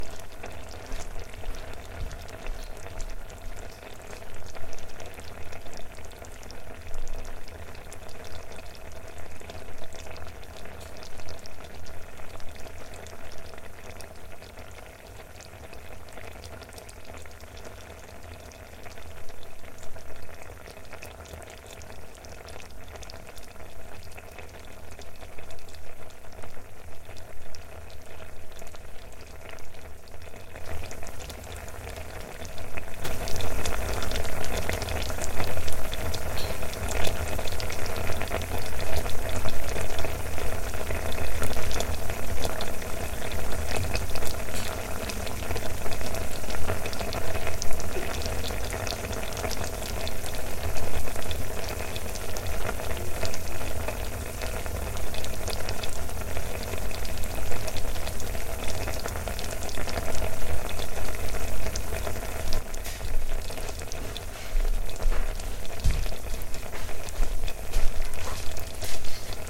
环境 " 冒泡的火锅 01
描述：冒泡的火锅，吐出臭气可闻的硫磺烟雾，产生这种强烈的冒泡/沸腾声音。在冰岛Seltún的温泉区内拍摄。从另一个角度记录。
标签： 沸腾 气泡 气泡 沸腾 起泡 液体
声道立体声